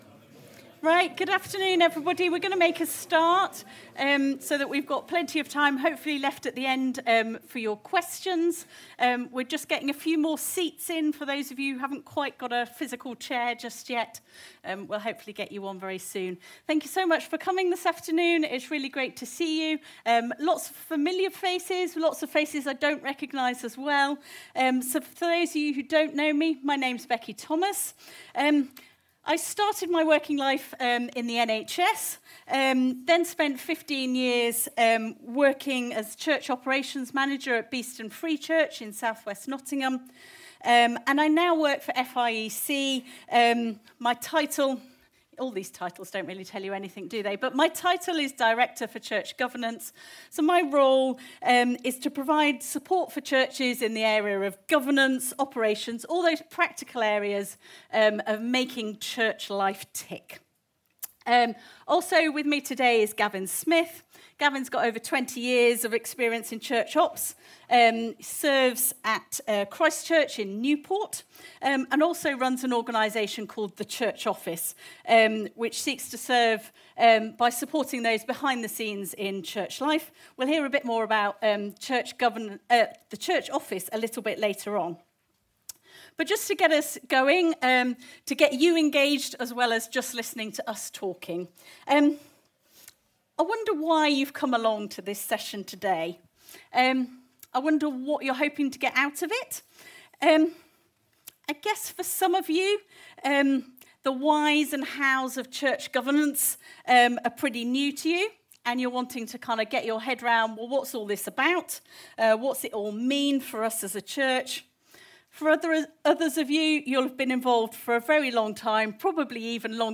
Series: Leaders' Conference 2025